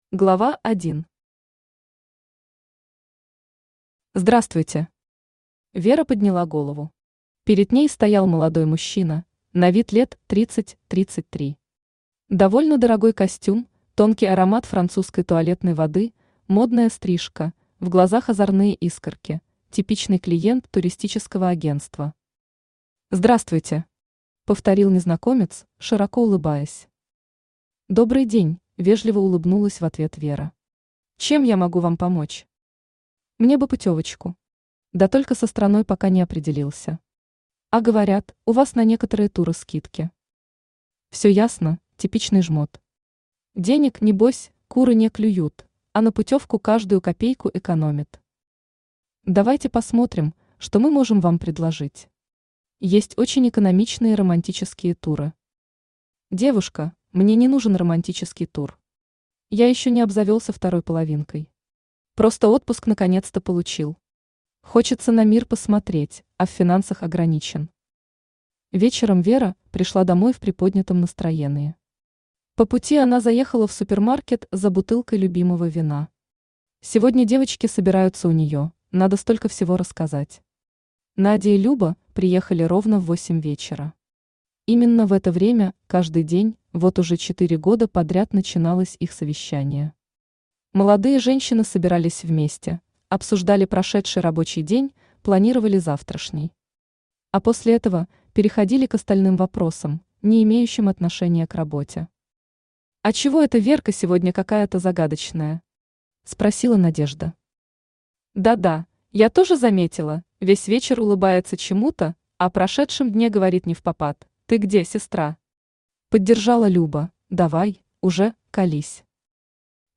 Aудиокнига Пиранья Автор Антонина Титова Читает аудиокнигу Авточтец ЛитРес.